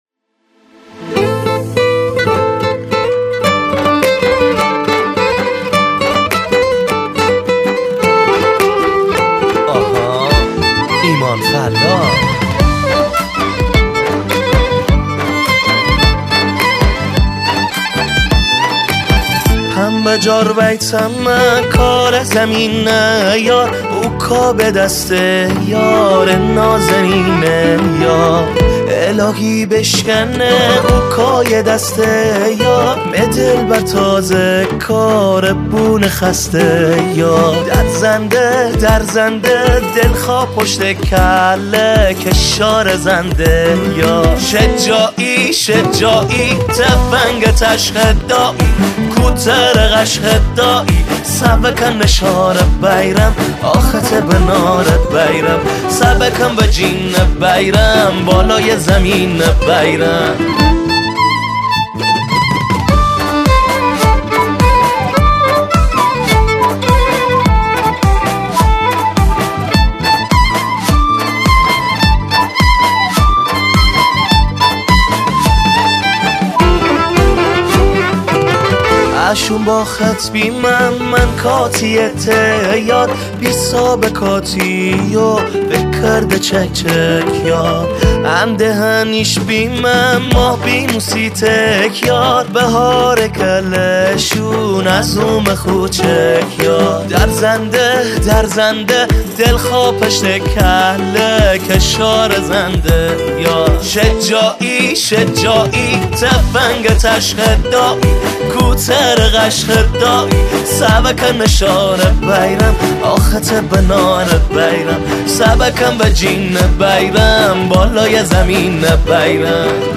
از خواننده محلی